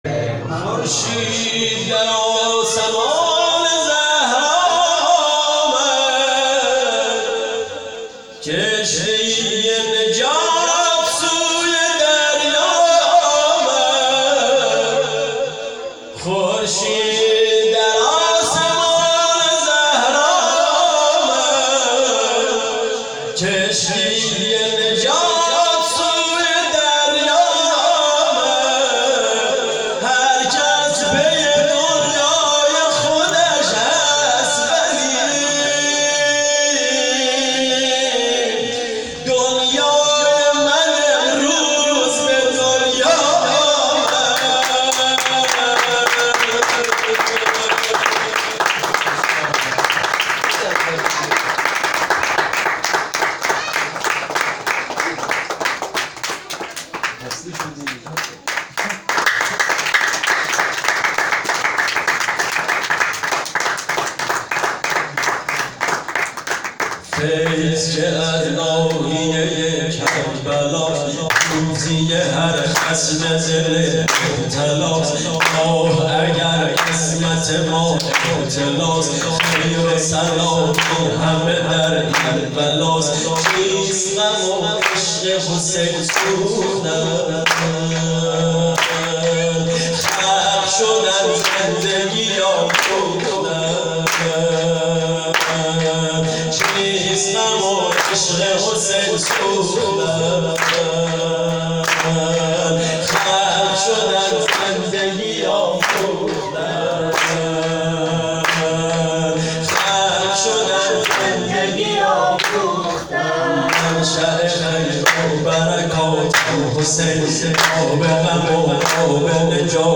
هیئت محبان المهدی(عج)آمل